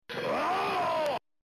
Grito haggar final fight
grito-haggar-final-fight.mp3